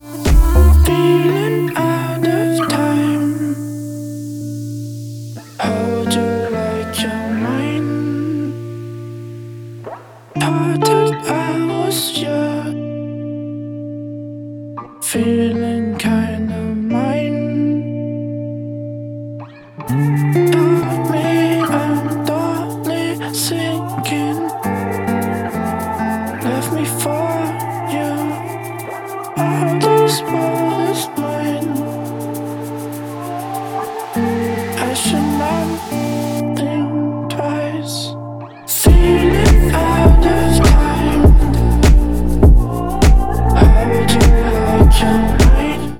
• Качество: 128, Stereo
красивый мужской голос
спокойные
красивая мелодия
Завораживающие
Стиль: Electronica/Downtempo